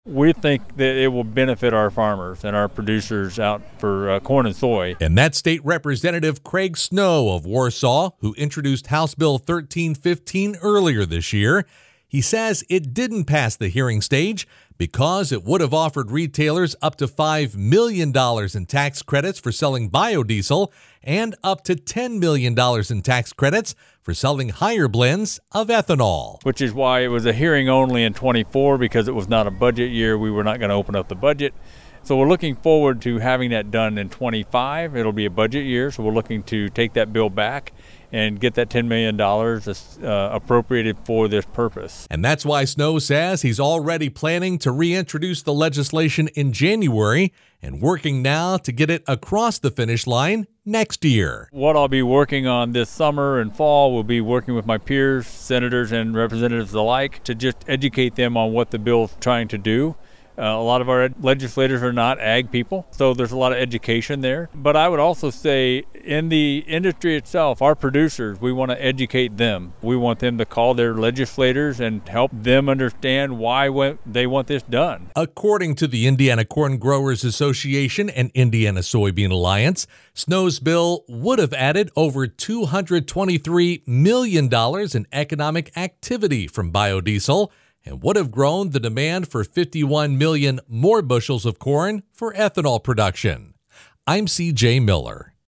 Snow spoke about his legislation as one of the guest panelists during the recent High-Performance Low Carbon Fuels Summit held at Andretti Global in Indianapolis and sponsored by the Indiana Corn Growers Association and Indiana Soybean Alliance.